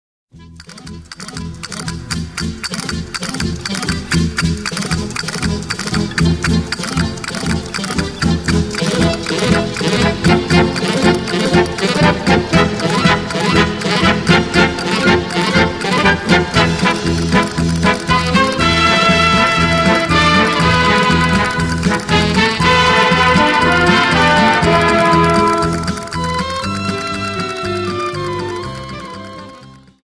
Dance: Paso Doble